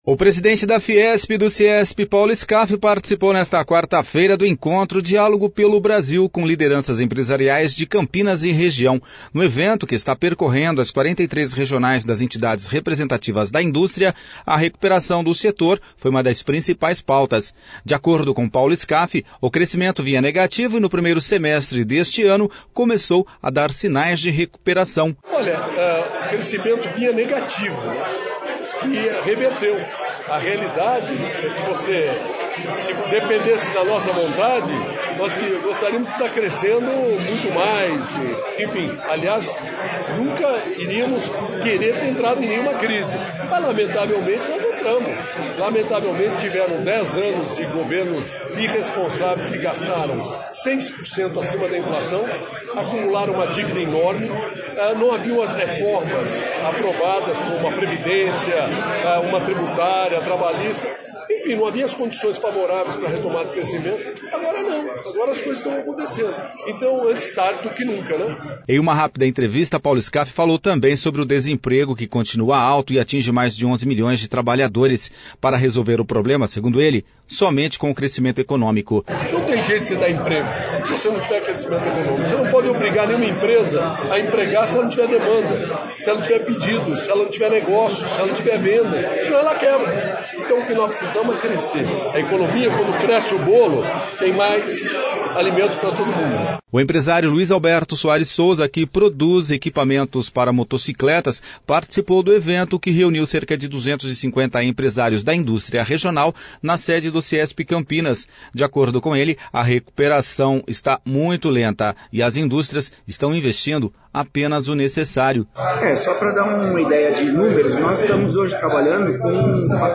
O presidente da FIESP e do CIESP, Paulo Skaf, participou nesta quarta feira do encontro Diálogo pelo Brasil com lideranças empresariais de Campinas e região.
Em uma rápida entrevista Paulo Skaf falou sobre o desemprego que continua alto.